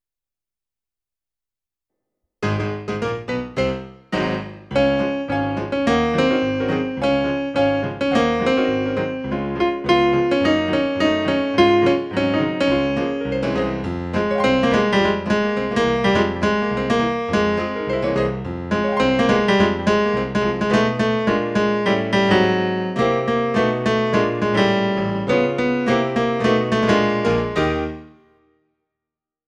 BARITONE/BASS: